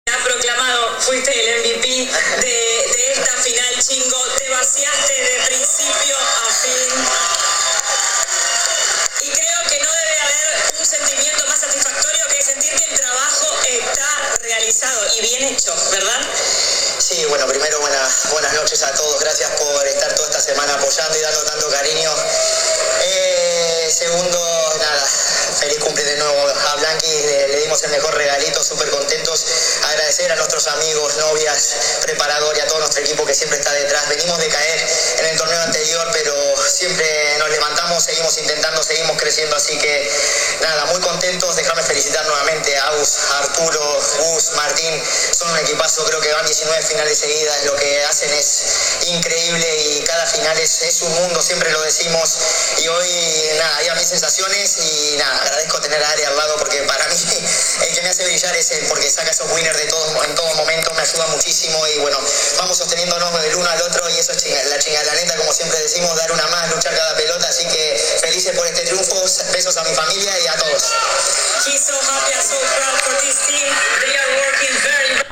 Fede habló en la premiación, tras ser elegido el  Most Valuable Player (Jugador Más Valioso) de la final.
Sus palabras fueron difundidas por la señal a cargo de la transmisión, las que compartimos con nuestros visitantes.
AUDIO CON LA PALABRA DE FEDERICO CHINGOTTO